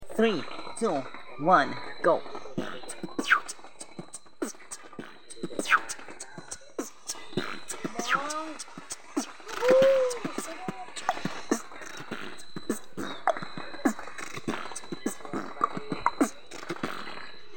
Beatbox